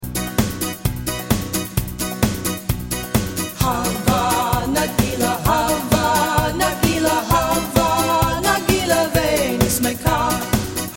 traditional Israeli hora